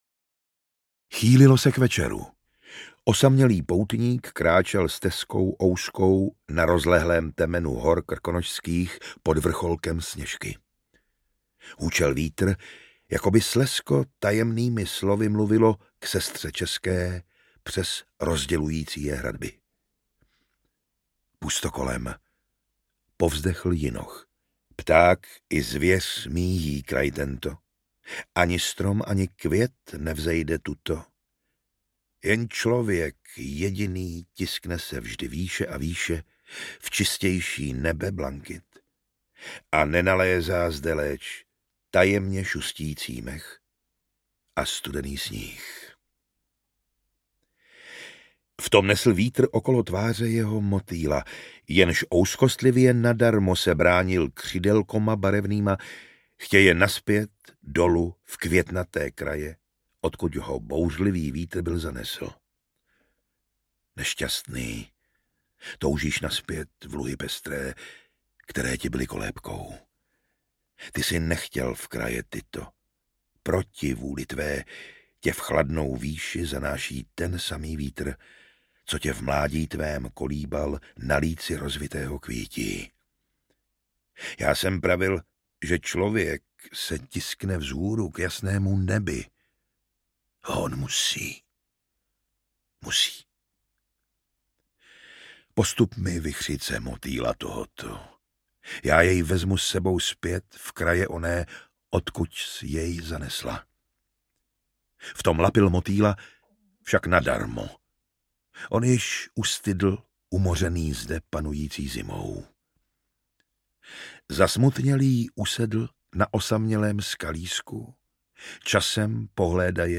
Audio knihaPouť krkonošská, Máj, Marinka a další
Ukázka z knihy
Vyrobilo studio Soundguru.